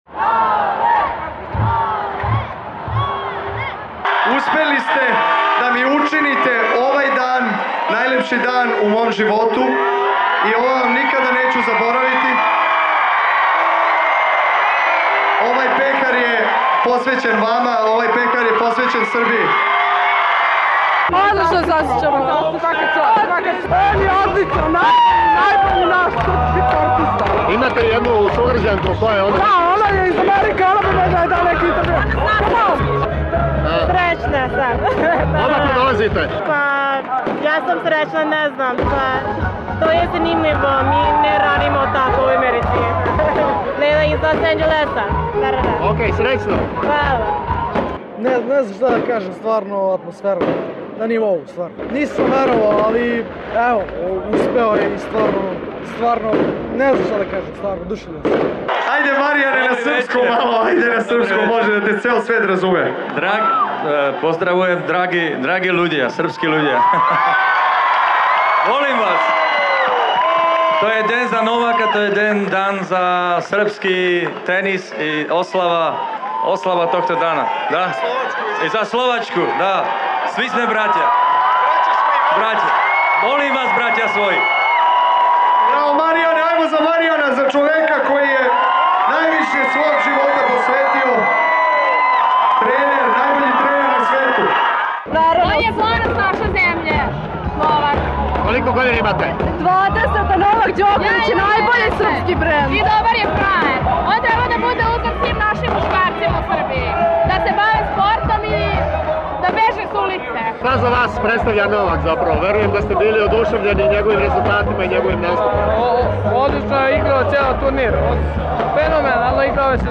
Doček Novaka Đokovića u Beogradu